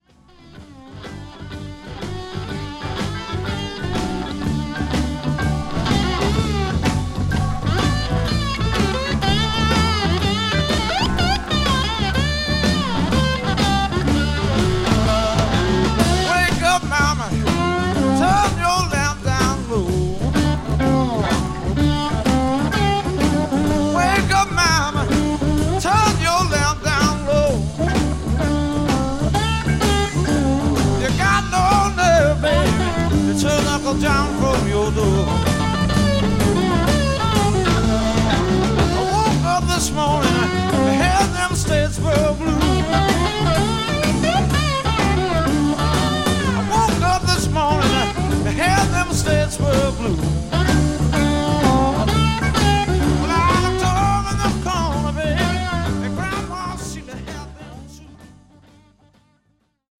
1971年の2枚組名ライブアルバム。